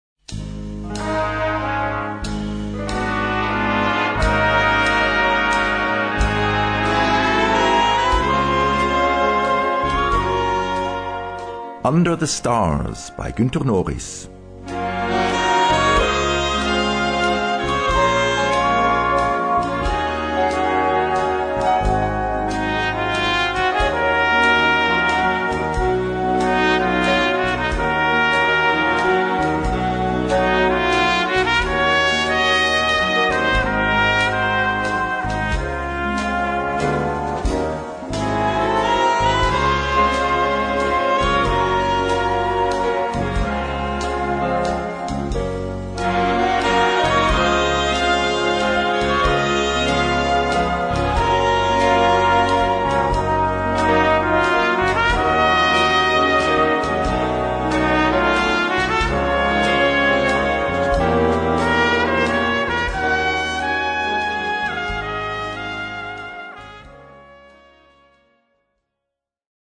Noten für Blasorchester, oder Brass Band.